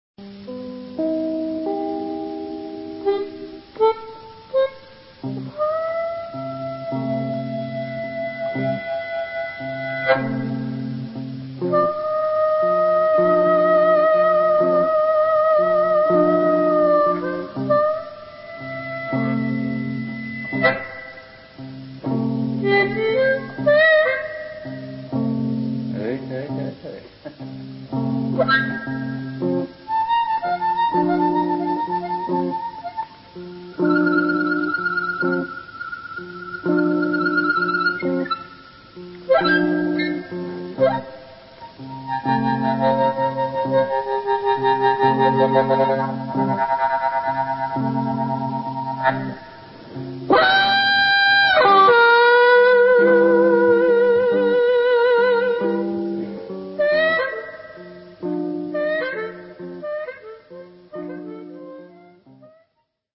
A live anthology of my work in bands from 1971 through 2001.